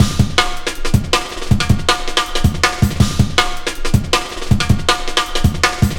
Index of /90_sSampleCDs/Zero-G - Total Drum Bass/Drumloops - 1/track 10 (160bpm)